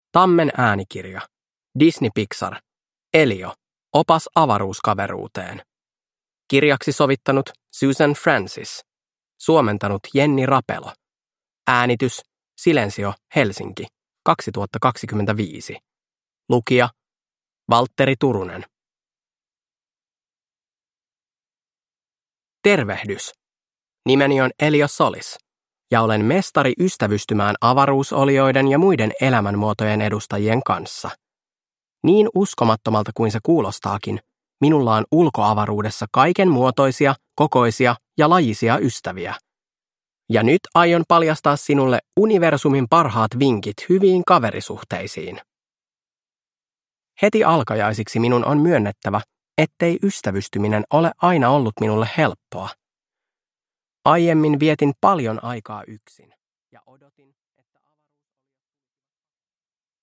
Disney Pixar. Elio. Opas avaruuskaveruuteen – Ljudbok